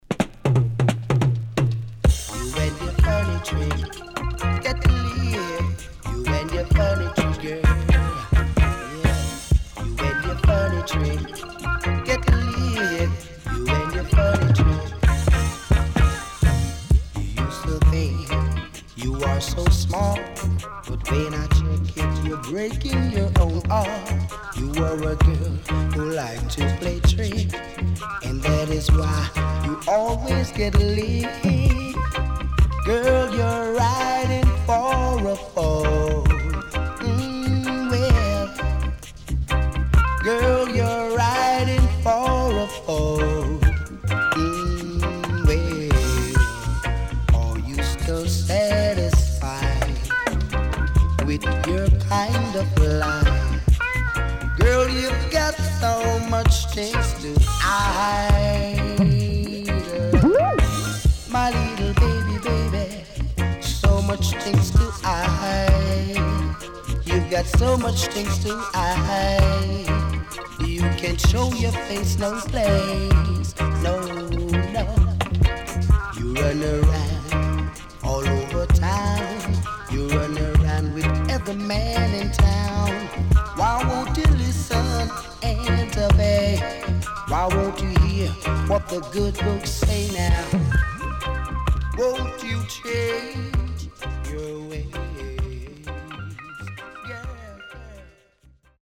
【12inch】
W-Side Good Vocal
SIDE A:少しノイズ入ります。